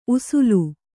♪ usulu